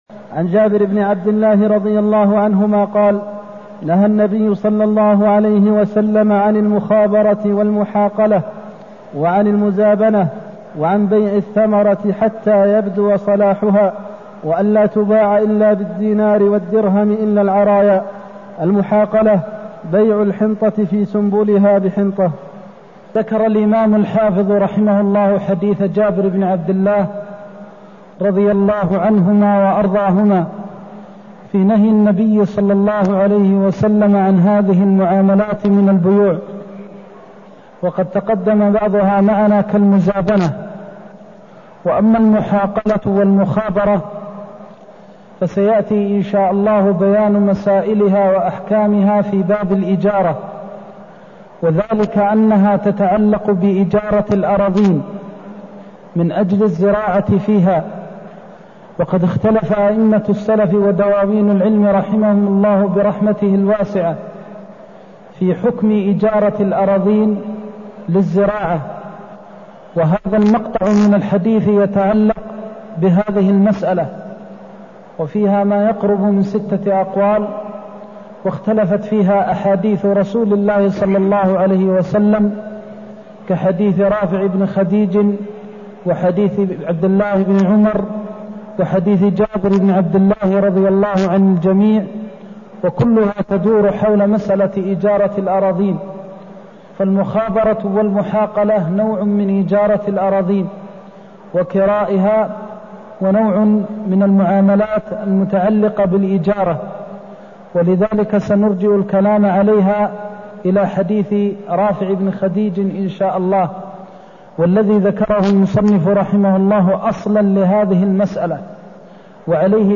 المكان: المسجد النبوي الشيخ: فضيلة الشيخ د. محمد بن محمد المختار فضيلة الشيخ د. محمد بن محمد المختار نهيه عن بيع المخابرة والمحاقلة (251)